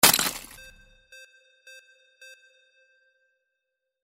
Ice Break 07
Stereo sound effect - Wav.16 bit/44.1 KHz and Mp3 128 Kbps
Tags: ice
previewIMP_ICE_BREAK_WBSD07.mp3